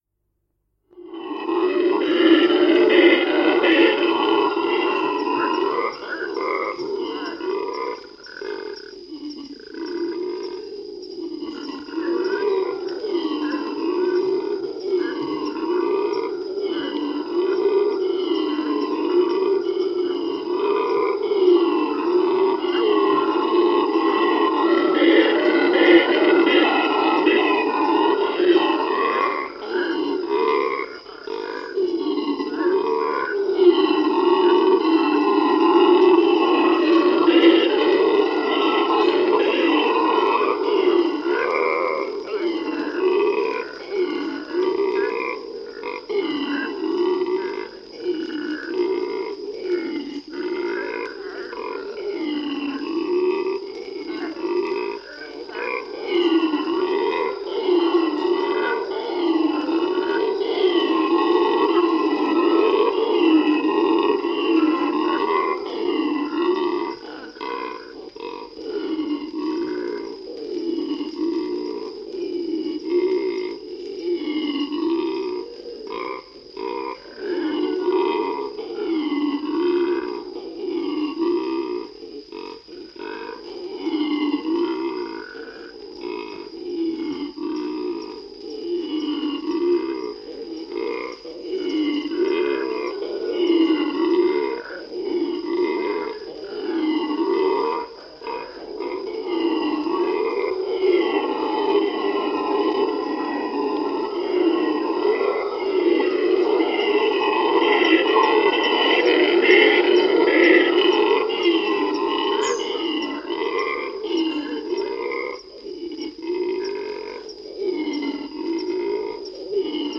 На этой странице собраны разнообразные звуки ревунов — от громких рыков до отдаленных эхо в джунглях.
Черная обезьяна-ревун в Аргентине